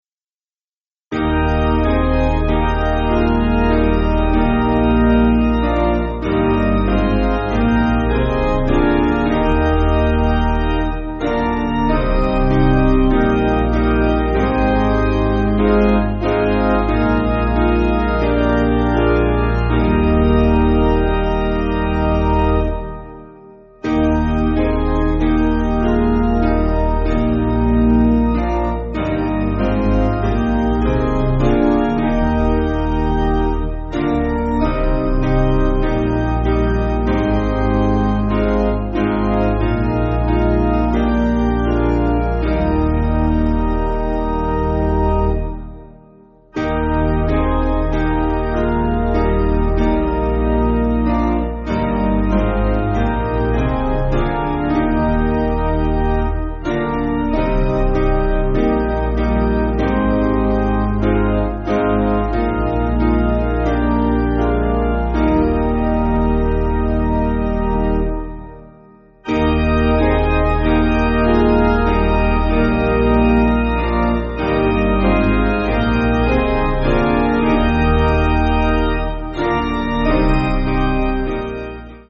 Basic Piano & Organ
(CM)   4/Eb